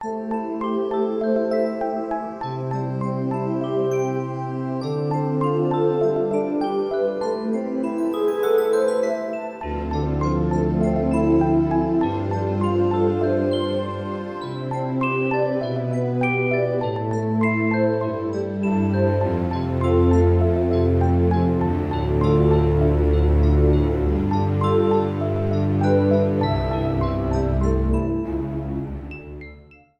Trimmed and fadeout
Fair use music sample